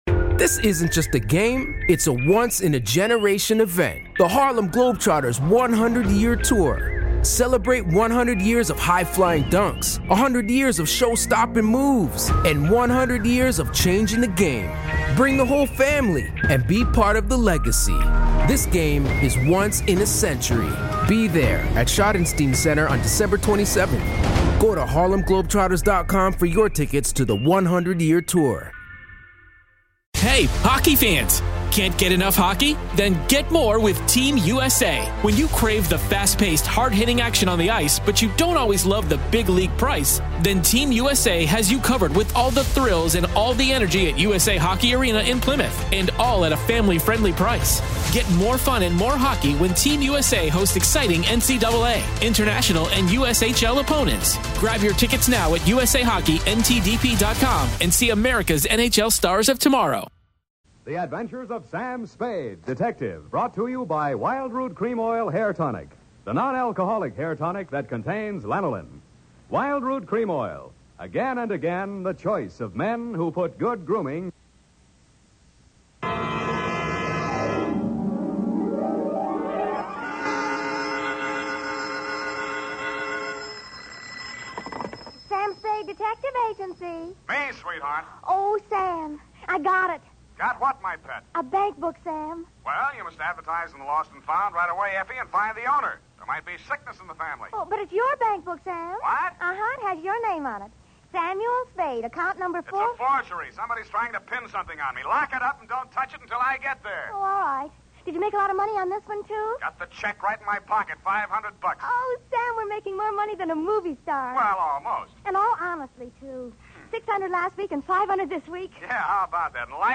What begins as a simple missing-person case turns into a dangerous hunt involving stolen identities, shady partners, and an unexpected twist only Spade could unravel. Step back into postwar San Francisco as Howard Duff stars as the wisecracking detective with a sharp mind and a sharper tongue.
Authentic 1940s radio drama · Restored vintage audio